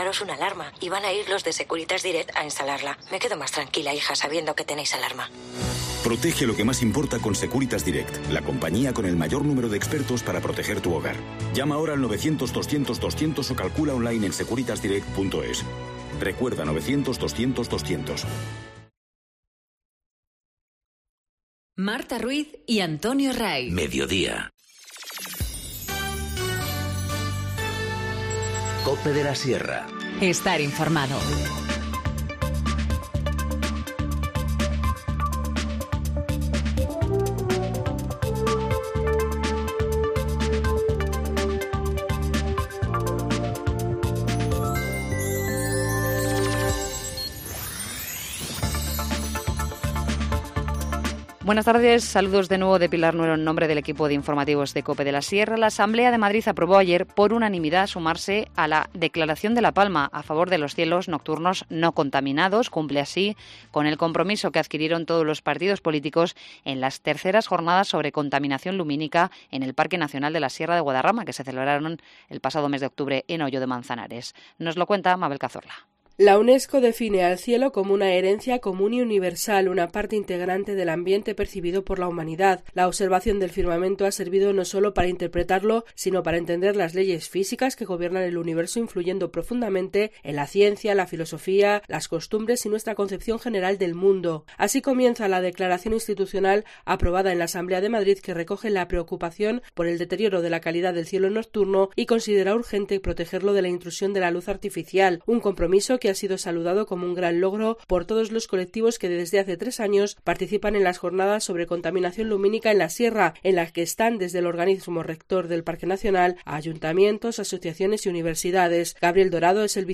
Informativo Mediodía 8 febrero- 14:50h